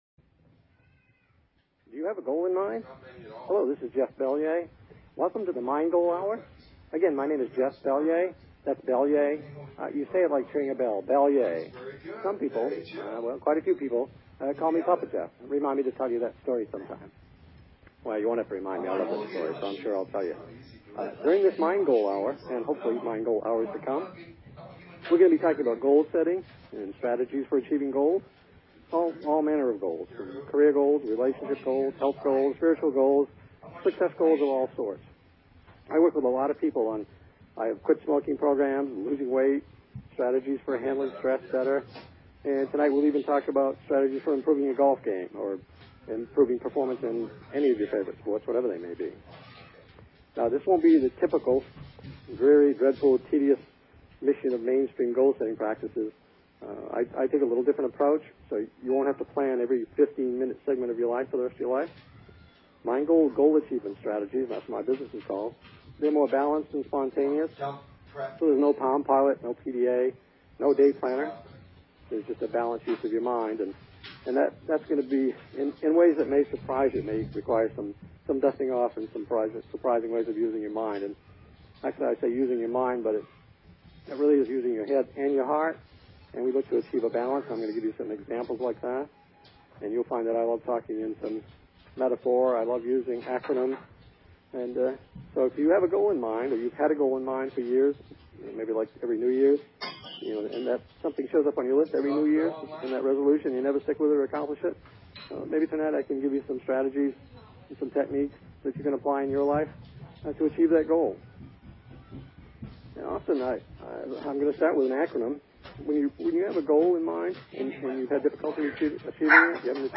Evening Trial, a talk show on BBS Radio!